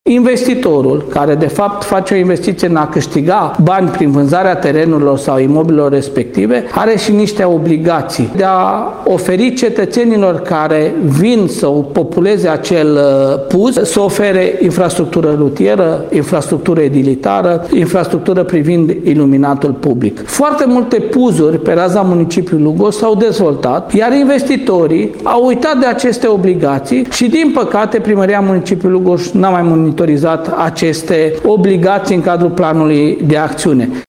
Declarația primarului a venit ca răspuns la nemulțumirile locuitorilor din zonele afectate.